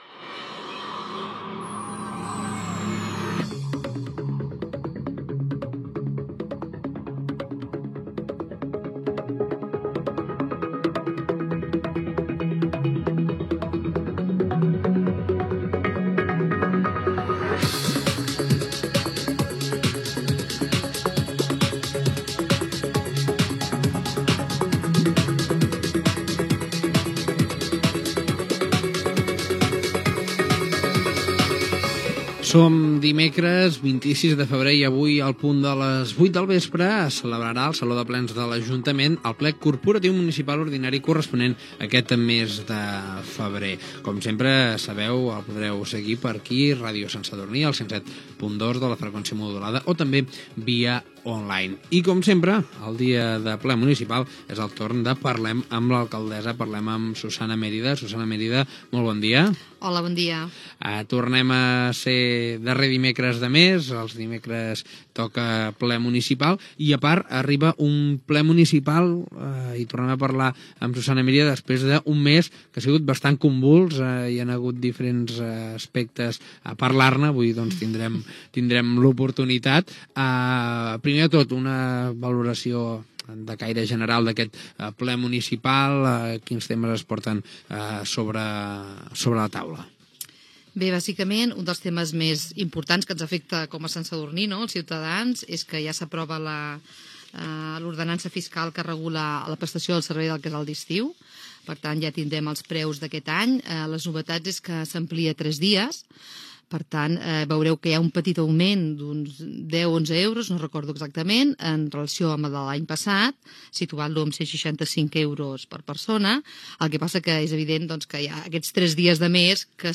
Presentació del programa i entrevista a l'alcaldessa de Sant Sadurní, Susanna Mérida.
Informatiu